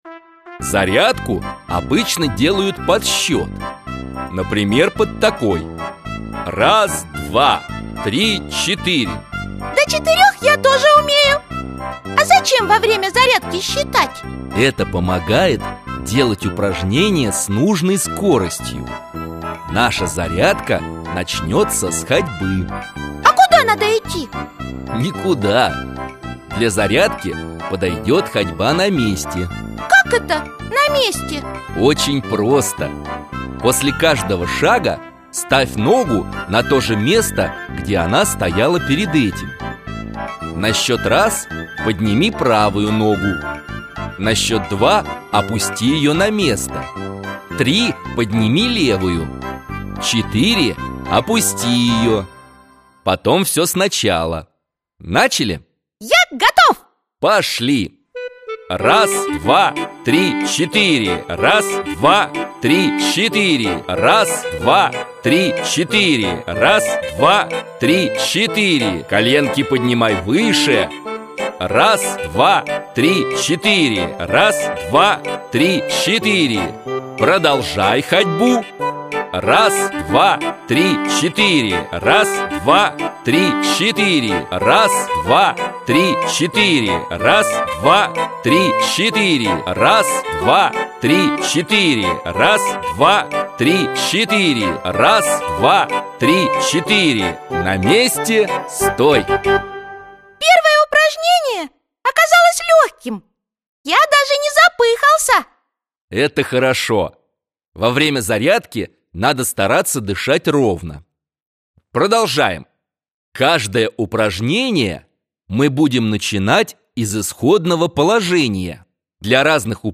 Аудиокнига Если хочешь быть здоров | Библиотека аудиокниг